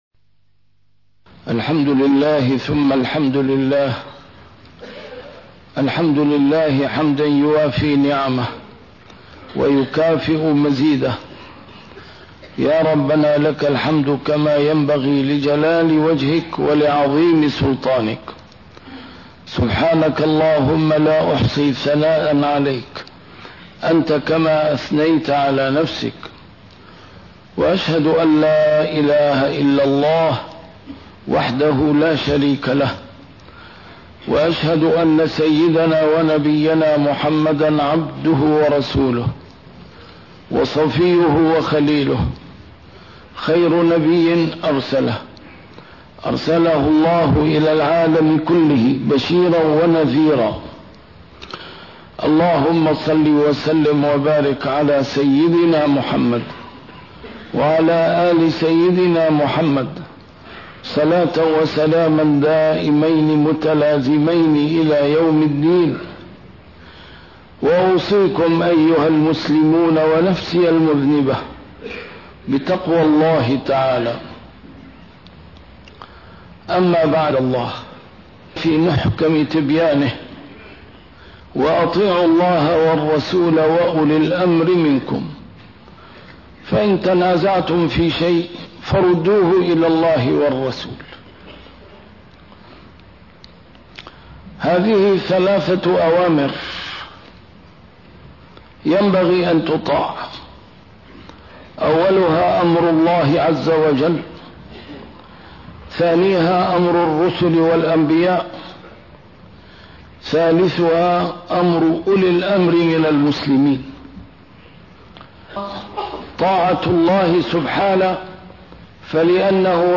نسيم الشام › A MARTYR SCHOLAR: IMAM MUHAMMAD SAEED RAMADAN AL-BOUTI - الخطب - أحكام القرآن تنسخ بكلمة ((قضية داخلية))